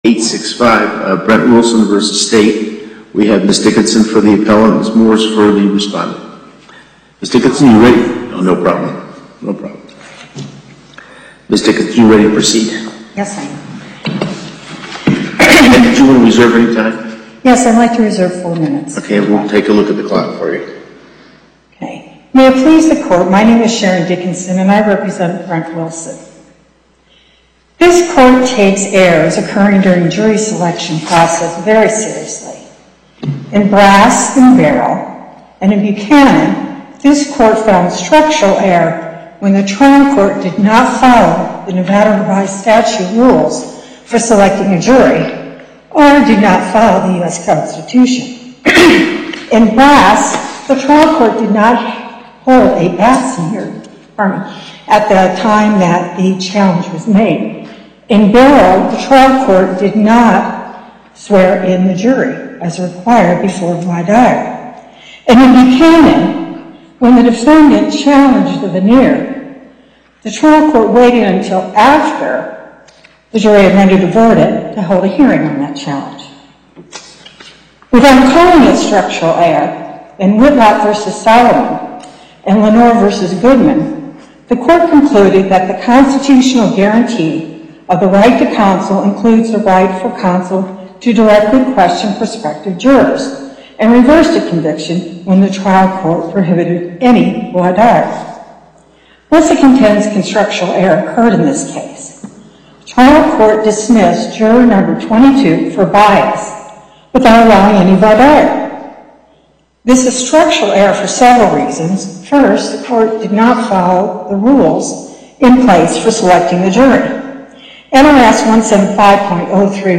Location: Las Vegas Before the Northern Nevada Panel, Justice Cherry, Presiding